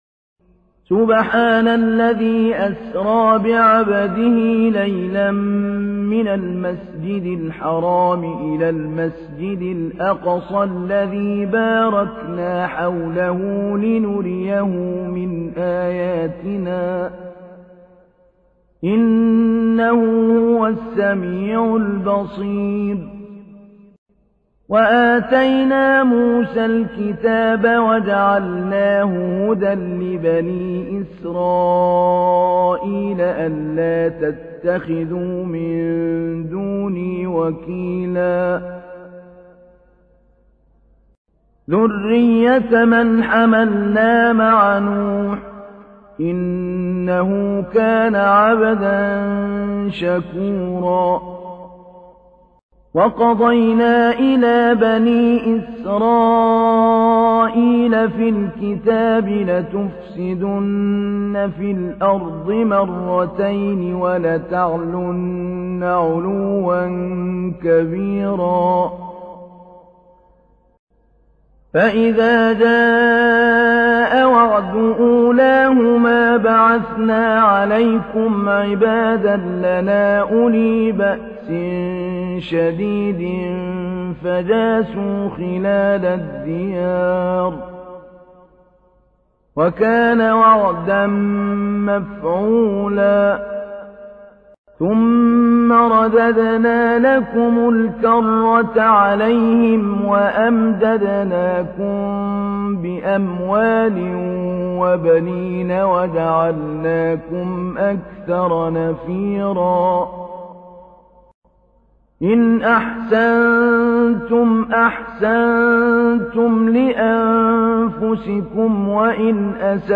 تحميل : 17. سورة الإسراء / القارئ محمود علي البنا / القرآن الكريم / موقع يا حسين